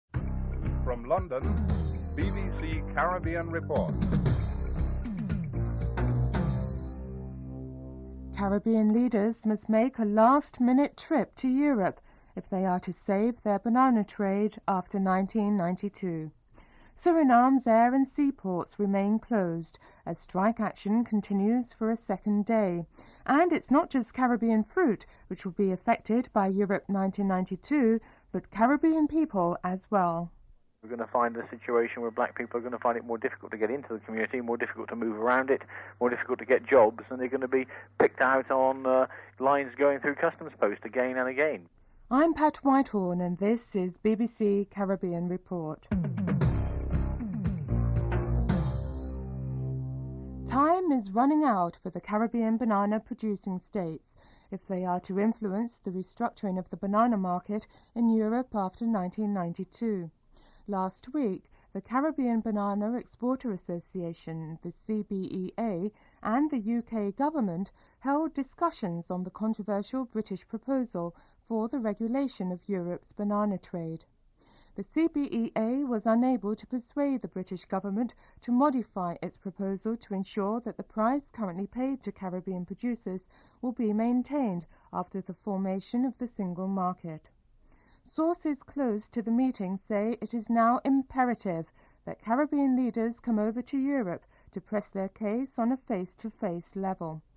1. Headlines (00:00-00:49)
Interviews with John Compton, Prime Minister of St. Lucia and Eugenia Charles, Prime Minister of Dominica (00:50-05:01)